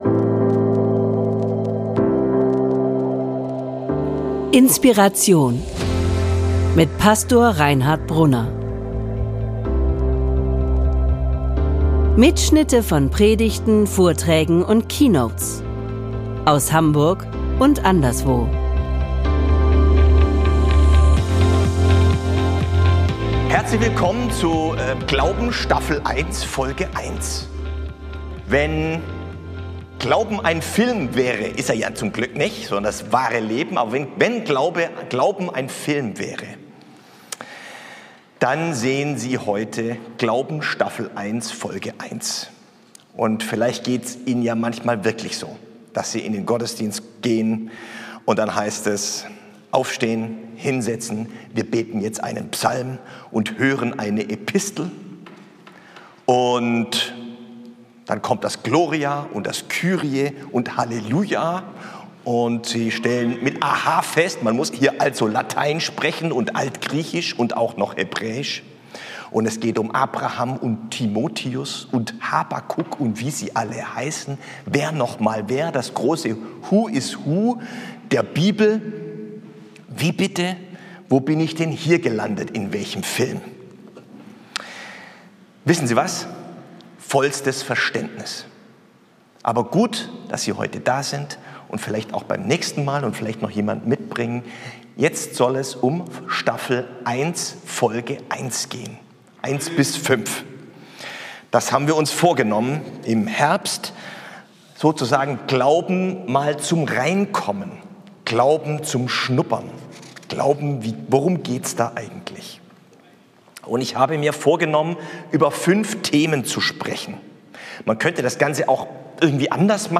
Glauben Staffel 1, Folge 1: Glück - und wie ich mich dahin aufmache ~ INSPIRATION - Predigten und Keynotes Podcast
Bei den Momentmal-Gottesdiensten im Herbst 2025 werde ich ganz einfach und praktisch darüber sprechen, wie man anfangen kann zu glauben. Worum geht es überhaupt beim Glauben?